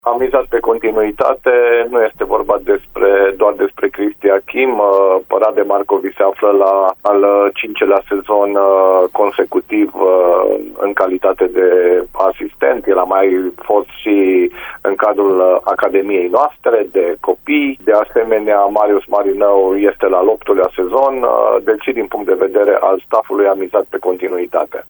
Invitat la Radio Timișoara în ediția de ieri a emisiunii Arena Radio